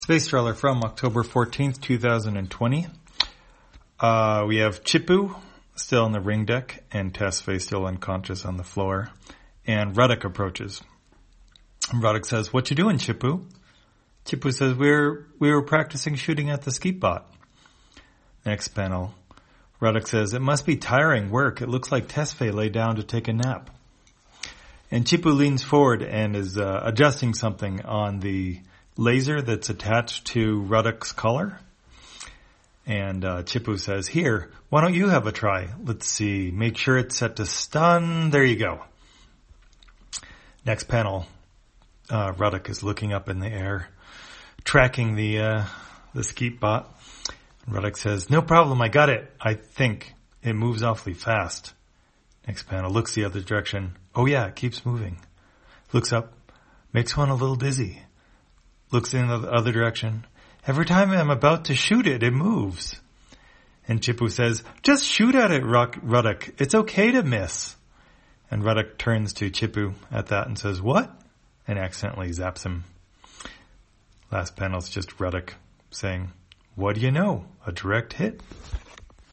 Spacetrawler, audio version For the blind or visually impaired, October 14, 2020.